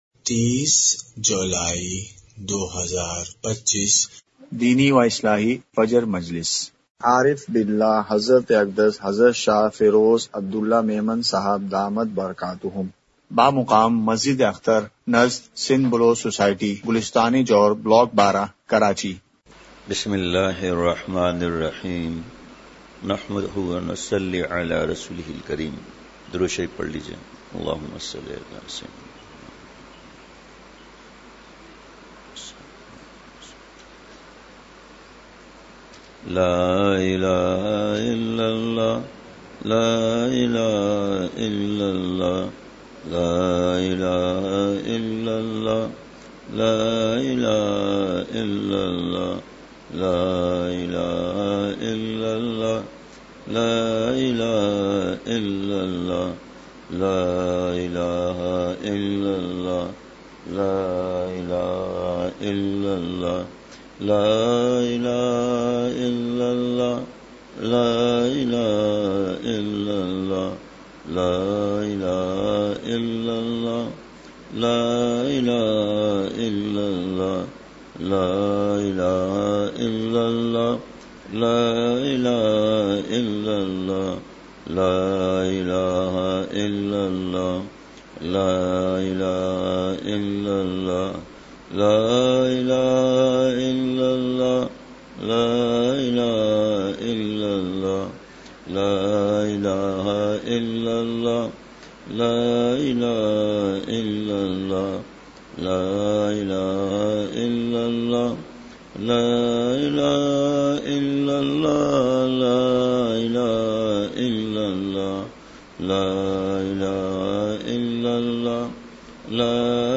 مقام:مسجد اختر نزد سندھ بلوچ سوسائٹی گلستانِ جوہر کراچی
مجلسِ ذکر:کلمہ طیّبہ۔۔۔!!